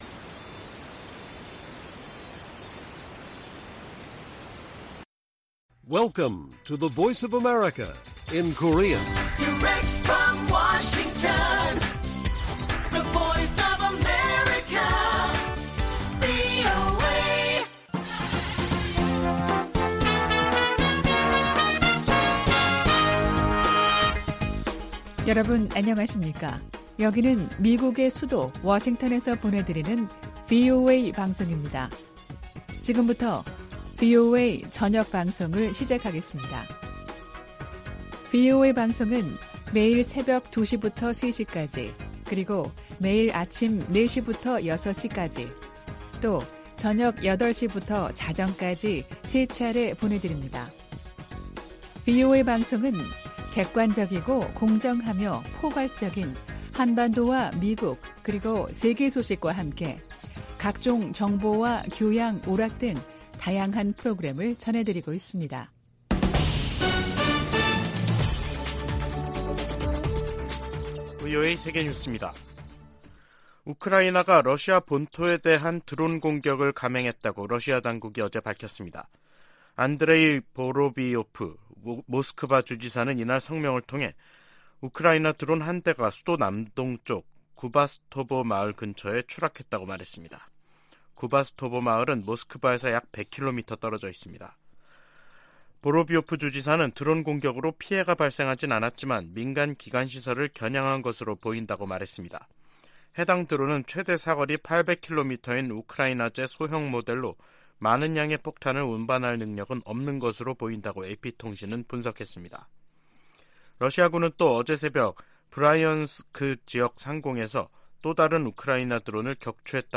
VOA 한국어 간판 뉴스 프로그램 '뉴스 투데이', 2023년 3월 1일 1부 방송입니다. 미국과 한국은 확장억제수단 운용연습(DSC TTX)을 성공적으로 진행했다며, 대북 확장억제 강화 필요성에 동의했다고 미 국방부가 밝혔습니다. 유엔 군축회의에서 한국 등이 북한의 핵과 탄도미사일 개발을 심각한 안보 도전이라고 비판했습니다. 윤석열 한국 대통령은 3.1절 기념사에서 일본은 협력 파트너로 변했다며 북 핵 대응 미한일 공조의 중요성을 강조했습니다.